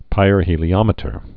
(pīrhē-lē-ŏmĭ-tər, pĭr-)